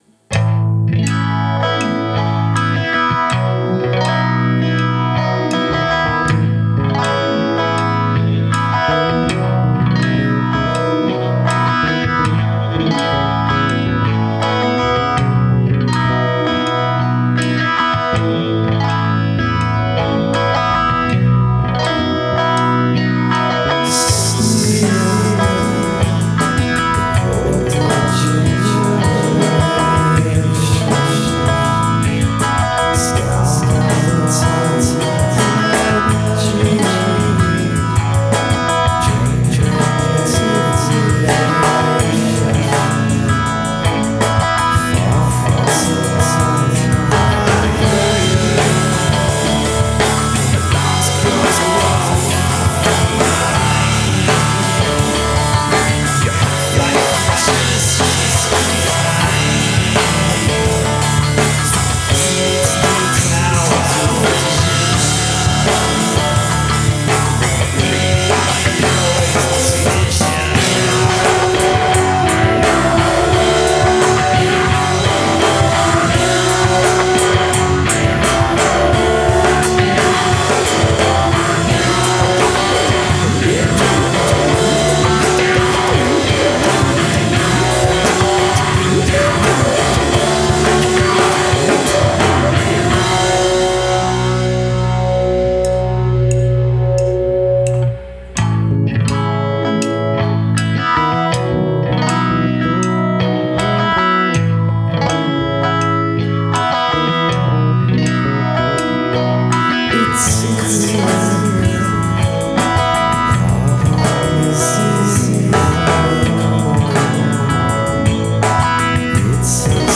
Lead Vox
Percussion
Guitar
Bass